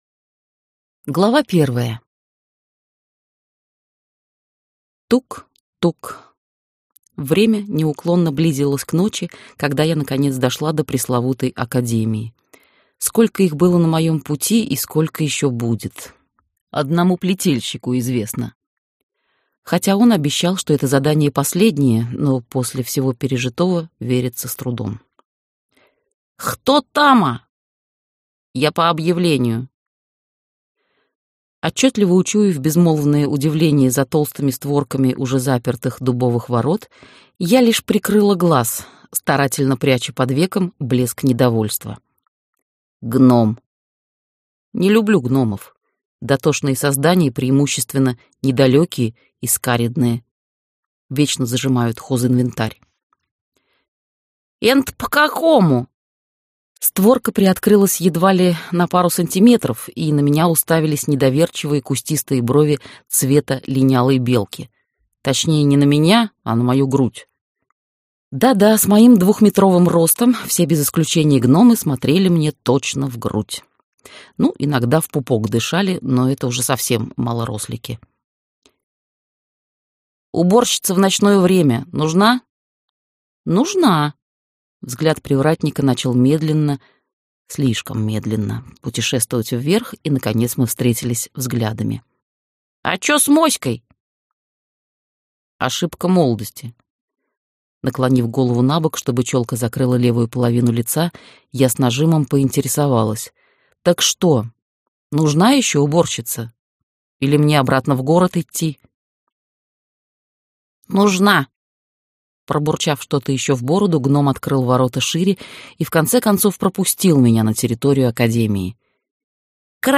Аудиокнига Заря в Академии Крови | Библиотека аудиокниг